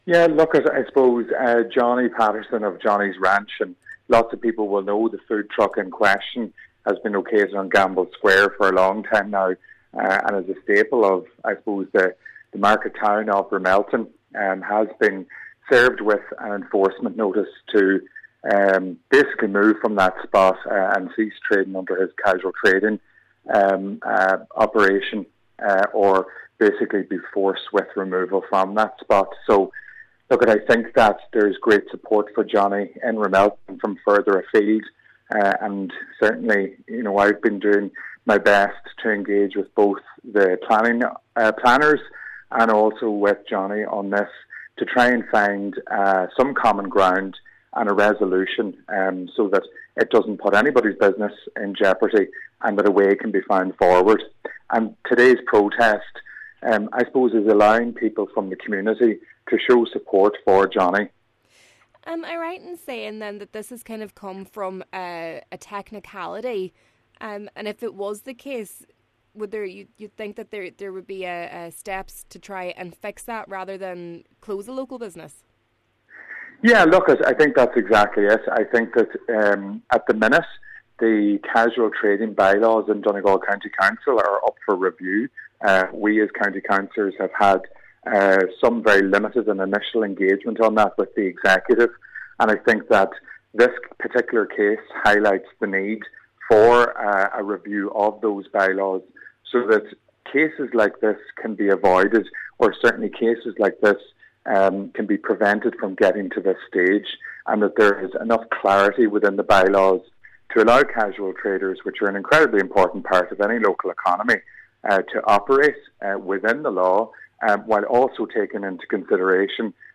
Cllr Declan Meehan says he will be in attendance: